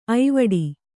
♪ aivaḍi